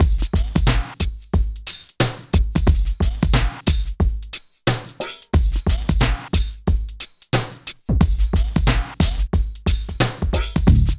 85 DRUM LP-L.wav